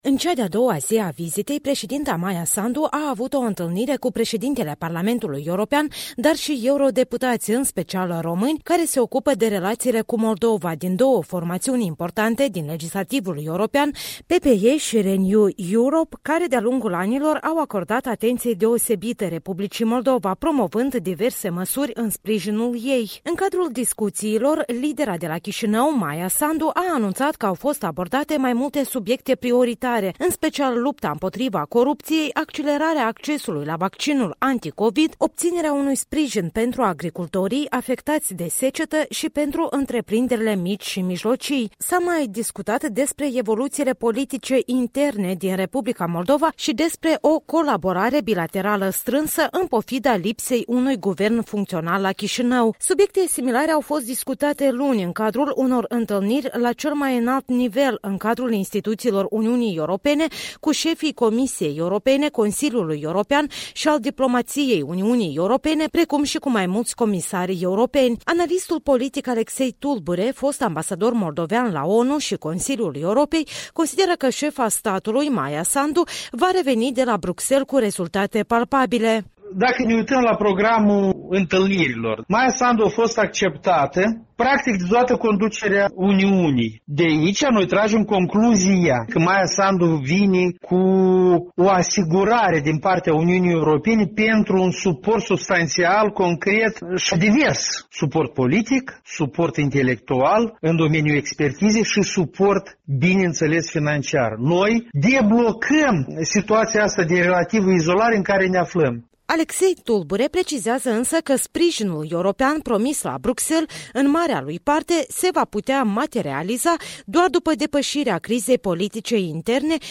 Corespondenta Europei Libere a pregătit o evaluare a discuțiilor avute de Maia Sandu, cerând opinii avizate, la Chișinău.